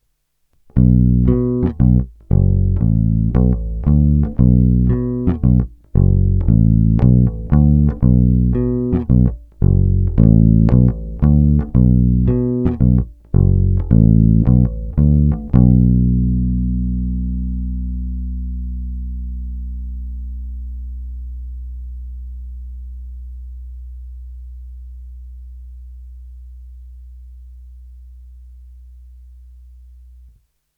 Nový snímač je víc vintage, zpět do šedesátkových let.
Není-li uvedeno jinak, nahrávky jsou provedeny rovnou do zvukovky a kromě normalizace ponechány bez úprav.
Hra nad snímačem